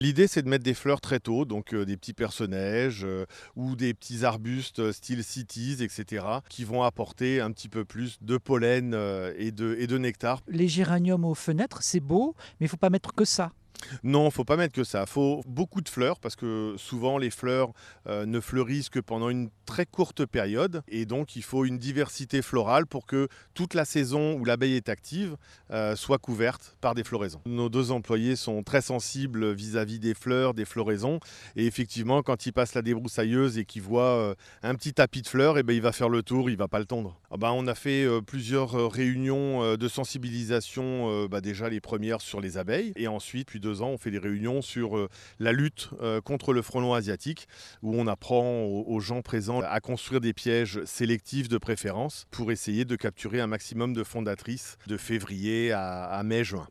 Reportage-1 France Bleu Berry – mai 2024